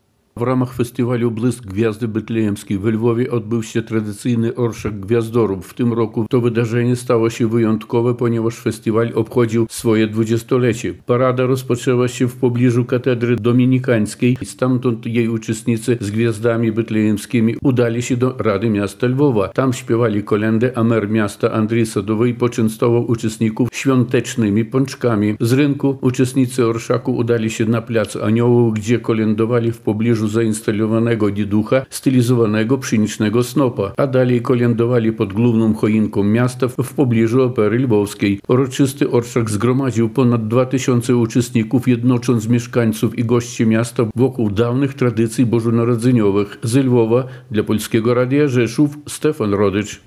Następnie śpiewali kolędy pod główną choinką miasta, w pobliżu Opery Lwowskiej.